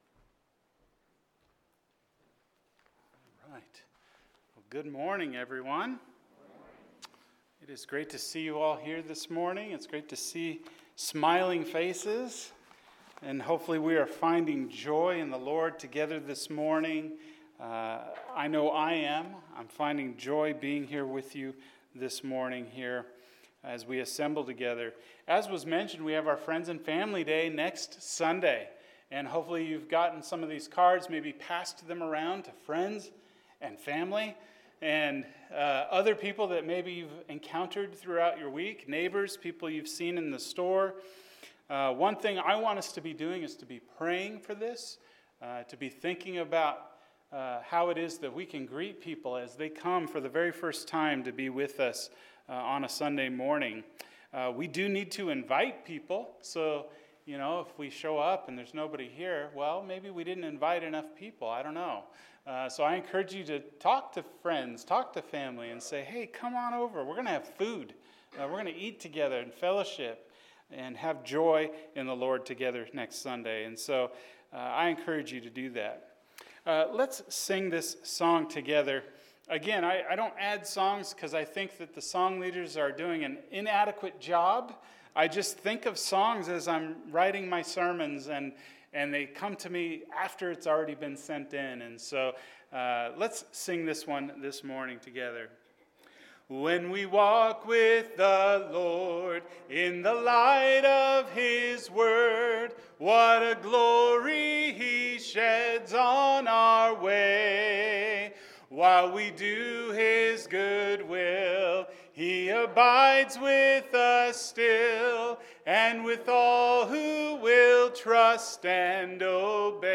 The Cross We Take Up – Luke 9:23-25, 57-62; 14:25-33 – Sermon